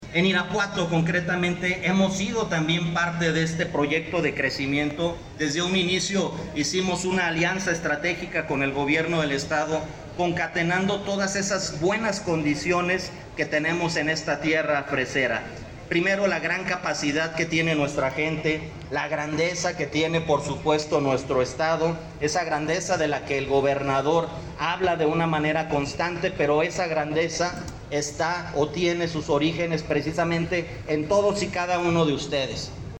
AudioBoletines
Rodolfo Gómez Cervantes, presidente municipal interino